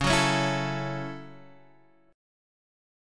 A6/C# chord